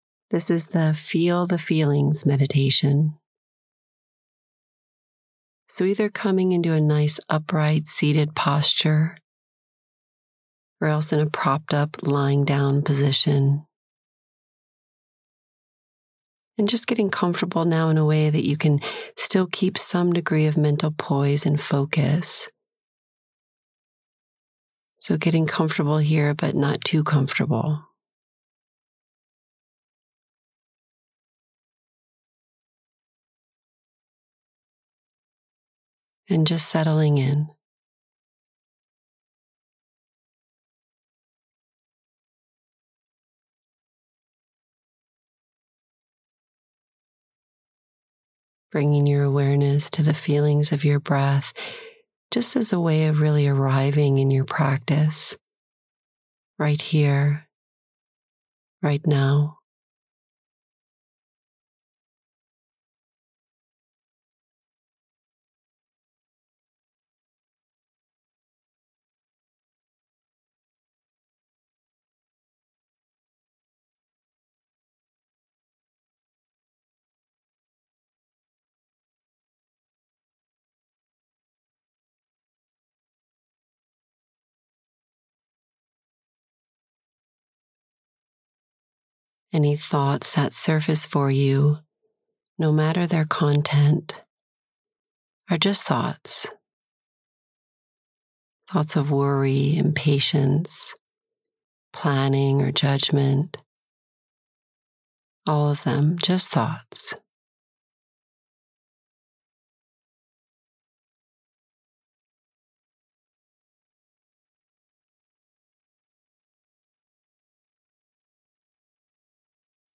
November2024-Campaign-Resource-Feel-the-Feelings-Meditation